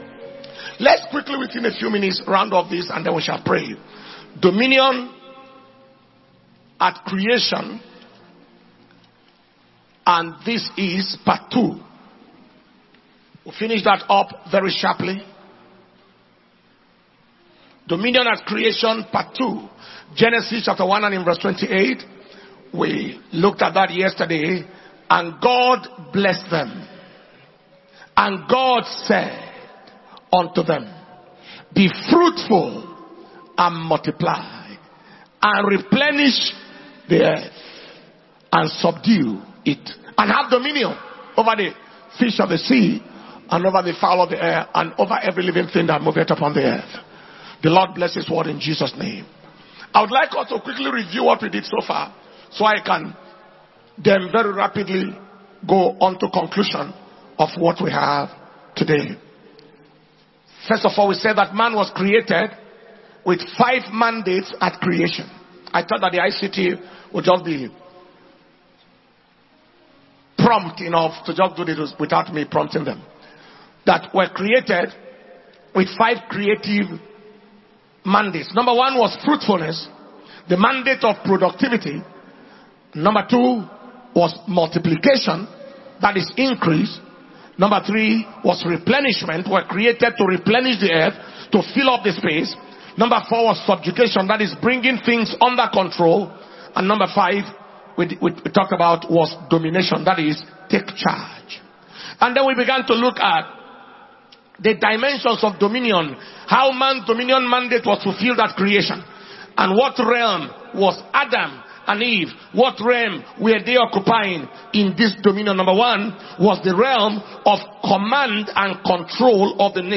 Kingdom Power and Glory World Conference 2024 day 2 morning session